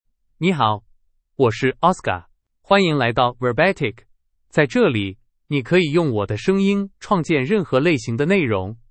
Oscar — Male Chinese (Mandarin, Simplified) AI Voice | TTS, Voice Cloning & Video | Verbatik AI
Oscar is a male AI voice for Chinese (Mandarin, Simplified).
Voice sample
Listen to Oscar's male Chinese voice.
Male